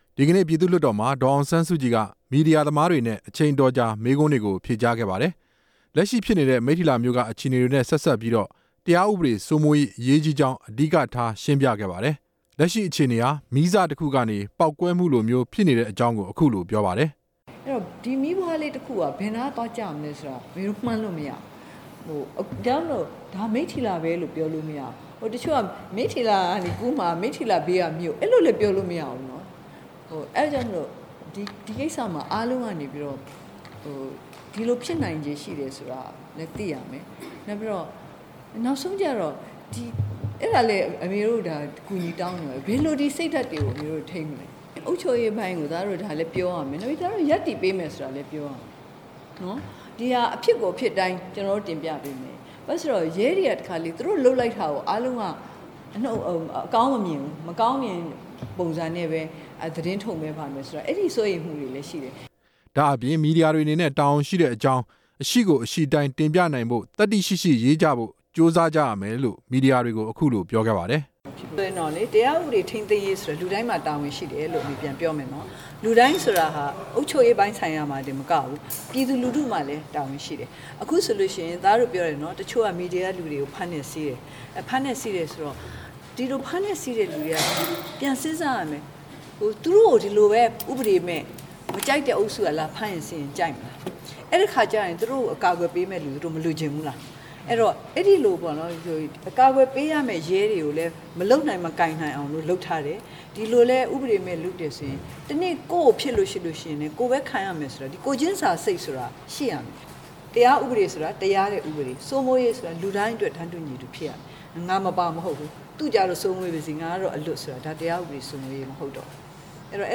ဒေါ်အောင်ဆန်းစုကြည်ရဲ့ ဖြေကြားချက်
ဒီကနေ့ လွှတ်တော်အဆောက်အအုံ I-၁၂ အဆောင်မှာ သတင်းထောက်တွေက မေးမြန်းစဉ် မှာ ဒေါ်အောင်ဆန်းစုကြည်က ဖြေကြားခဲ့တာပါ။